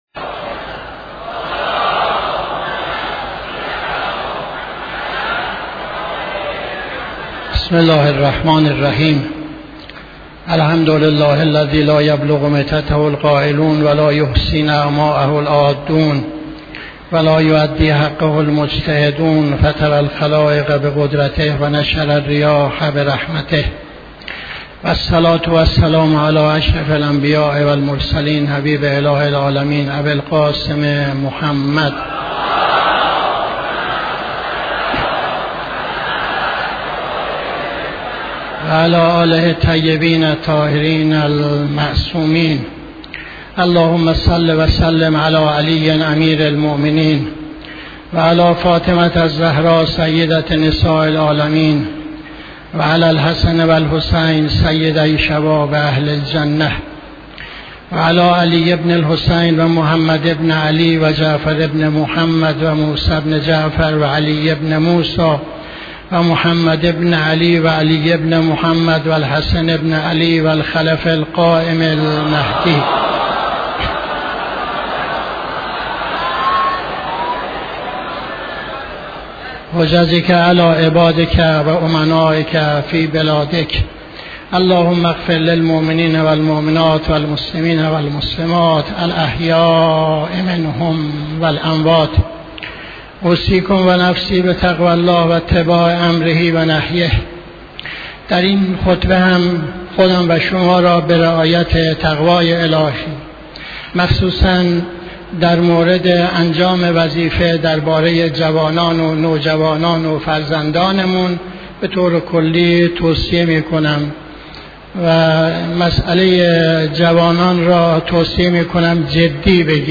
خطبه دوم نماز جمعه 19-05-80